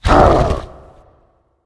spawners_mobs_balrog_attack.3.ogg